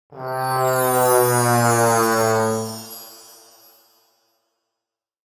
Sad Trombone Sound
Funny-fail-trombone-slide-sound-effect.mp3